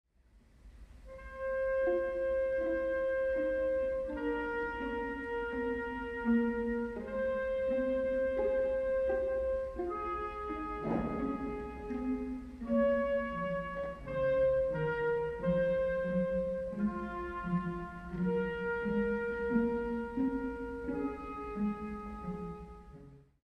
Ostatnia część rozpoczyna się powoli, majestatycznie, z wielką mocą.
Niestety jakość dźwięku nie jest satysfakcjonująca i także z tego względu nie jest to nagranie, które mógłbym polecić każdemu z czystym sumieniem.